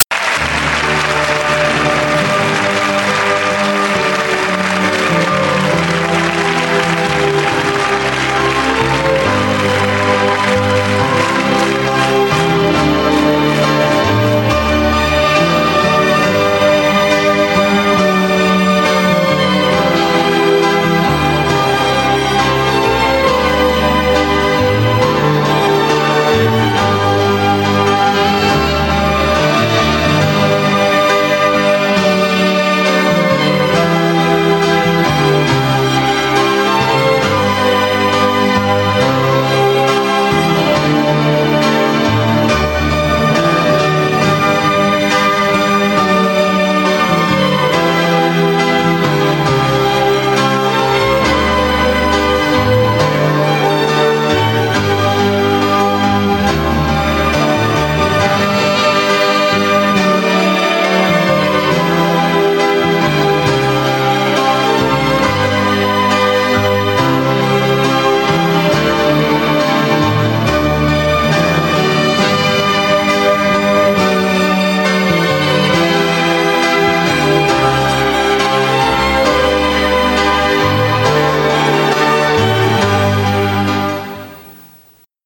TV-Radio en direct (hors concerts)